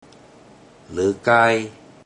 Click each Romanised Teochew word or phrase to listen to how the Teochew word or phrase is pronounced.
ler42kai1 (emphasis ler4kai10)